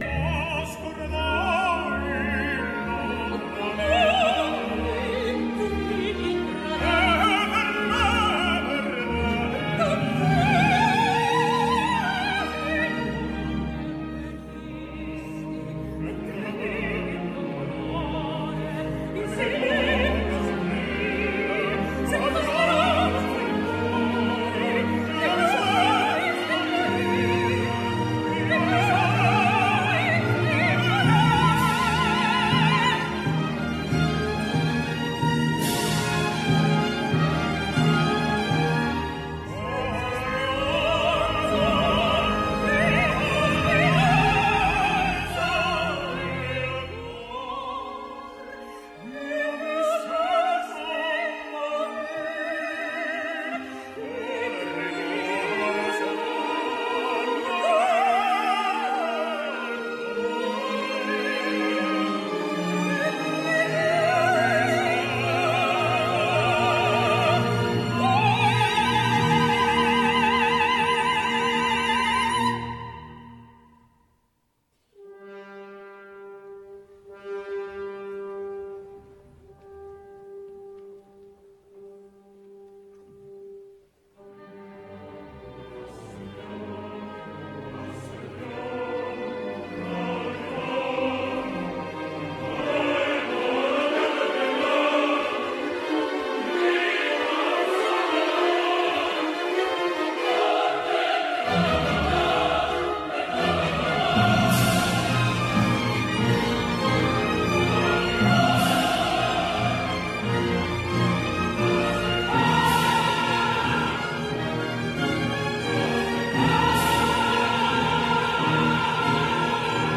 Concert Version
Recorded Live
José Cura and cast in Genova's concert version of Le Villi, March 07